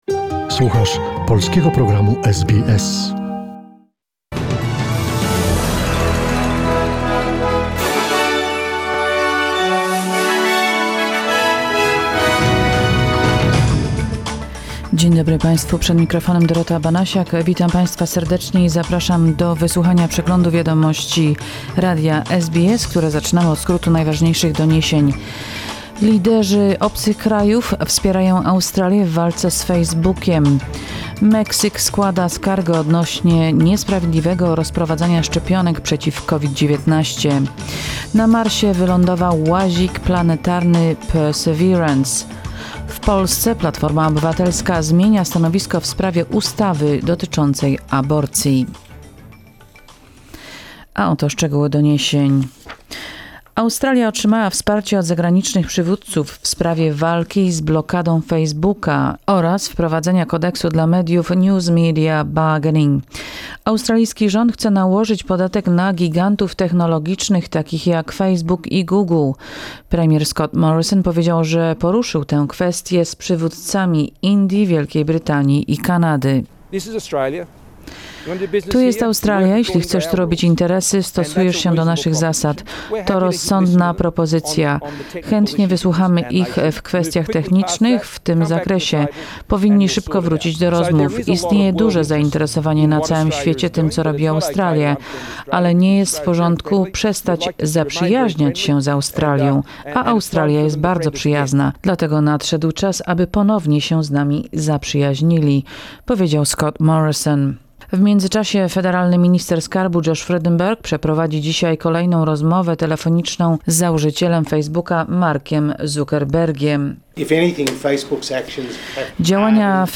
Wiadomości SBS, 19 lutego 2021 r.